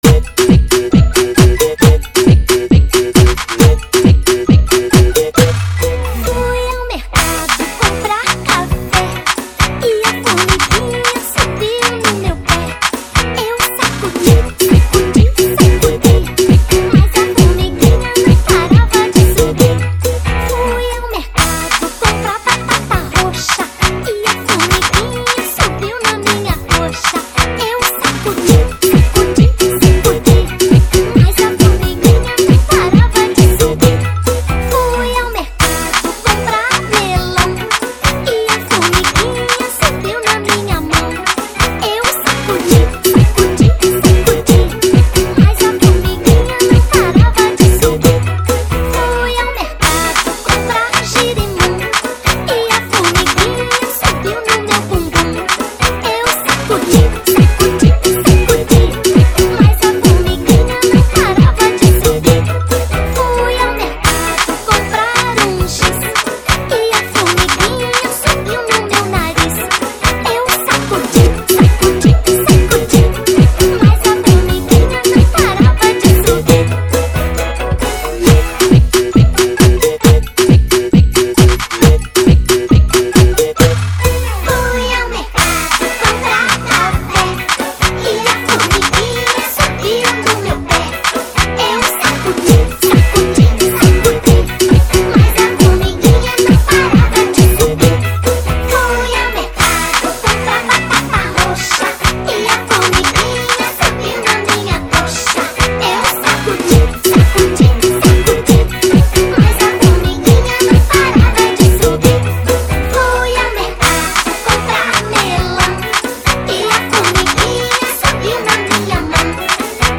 2025-04-17 22:44:01 Gênero: Funk Views